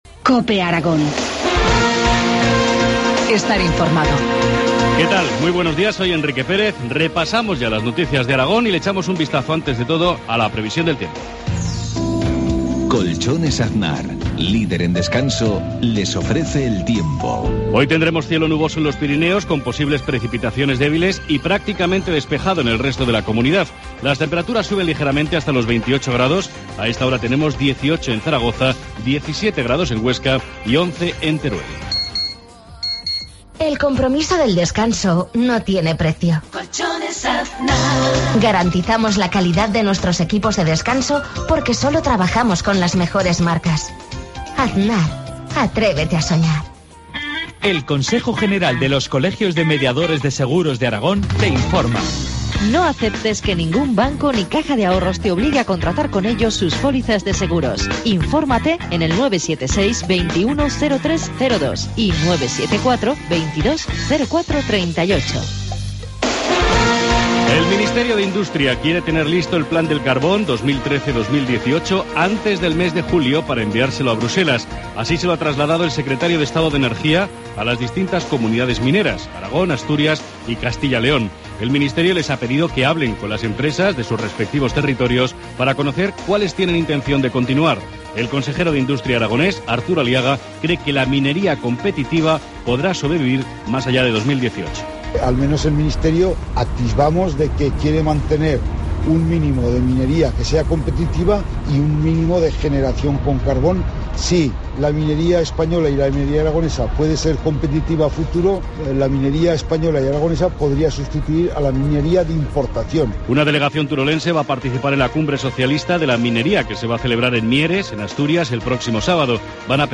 Informativo matinal, martes 11 de junio, 7.53 horas